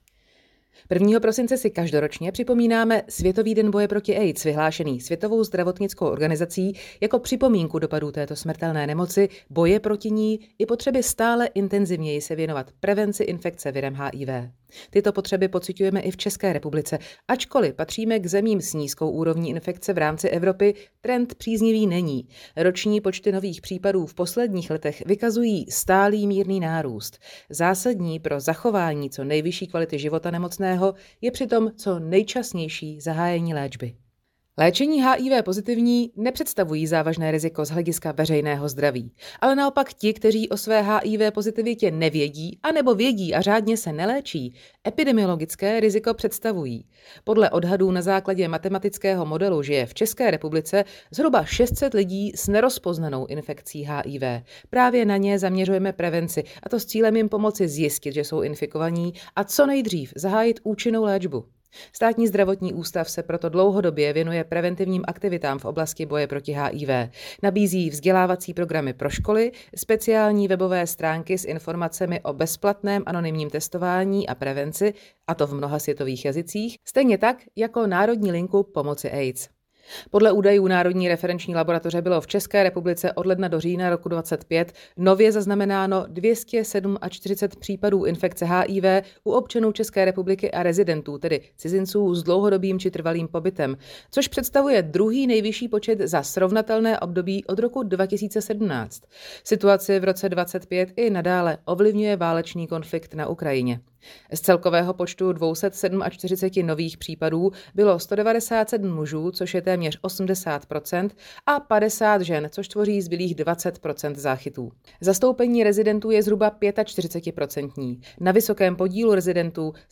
Zvuk k tiskové zprávě
Text tiskové zprávy v mluveném slovu